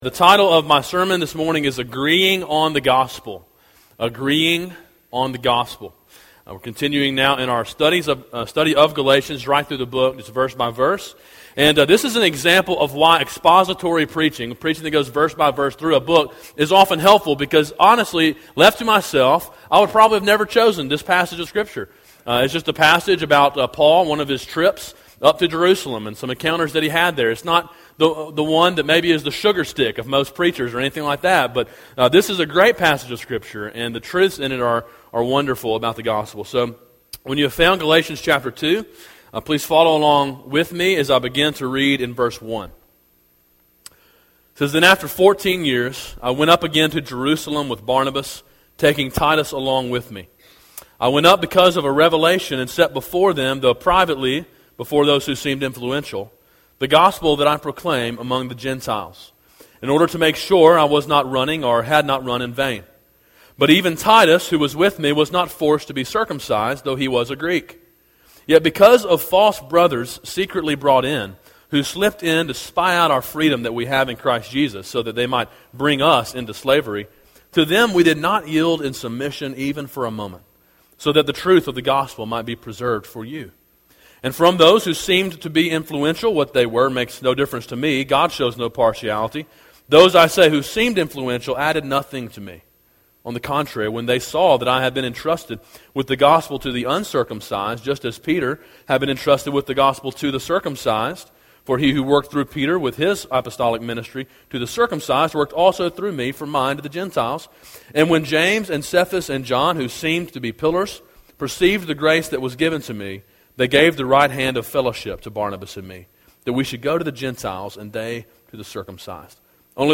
A sermon in a series on Galatians titled Freedom: A Study of Galatians.